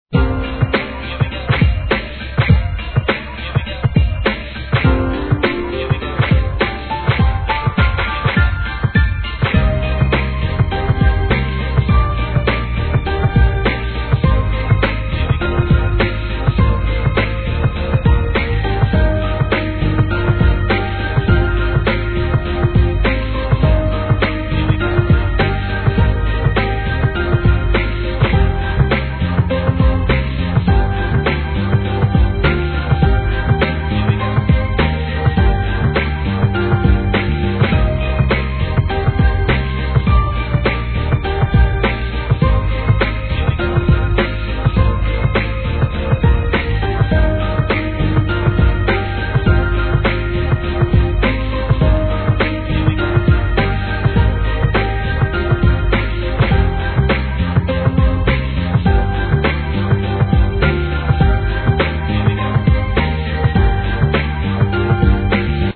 JAPANESE HIP HOP/R&B